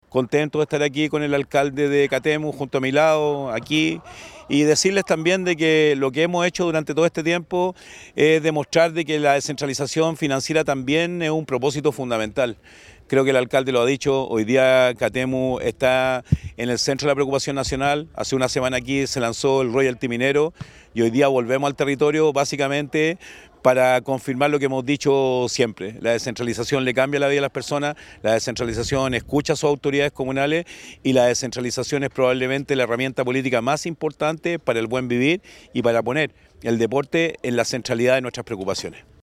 Al respecto el Gobernador Regional de Valparaíso, Rodrigo Mundaca, manifestó su alegría por seguir avanzando en la descentralización.
Gobernador.mp3